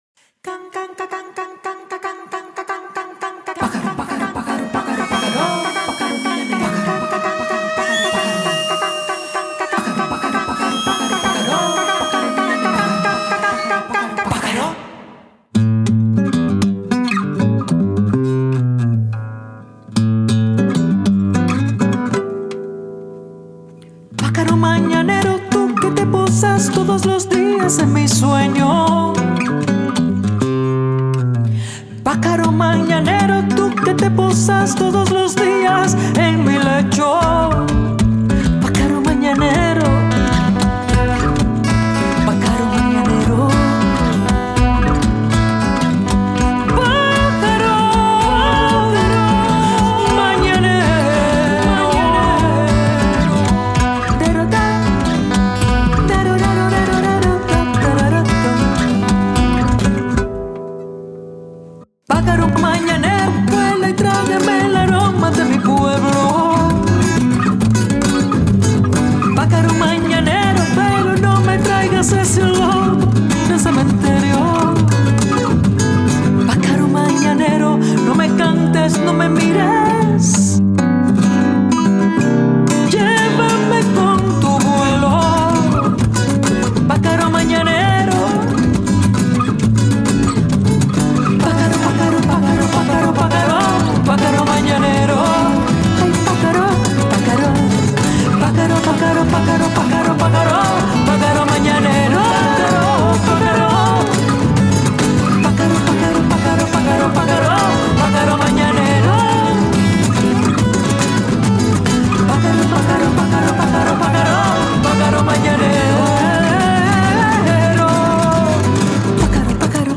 Canción contemporánea
Lo grabé aquí, en Medellín, Colombia